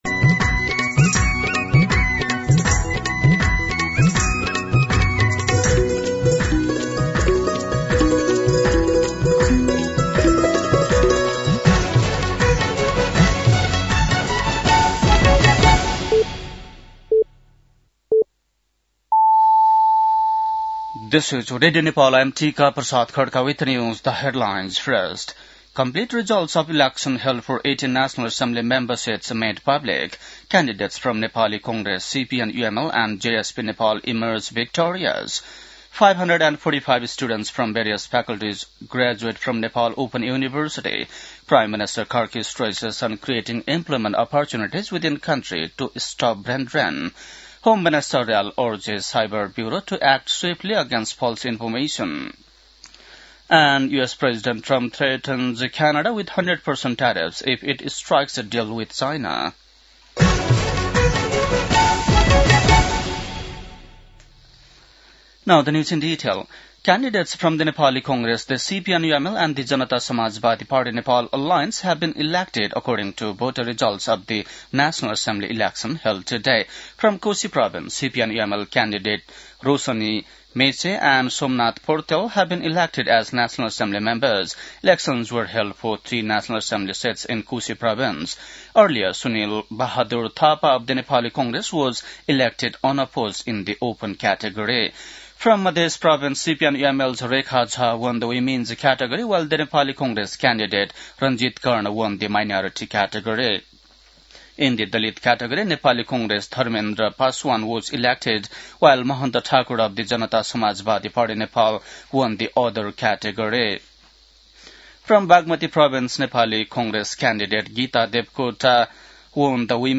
बेलुकी ८ बजेको अङ्ग्रेजी समाचार : ११ माघ , २०८२
8-pm-english-news-10-11.mp3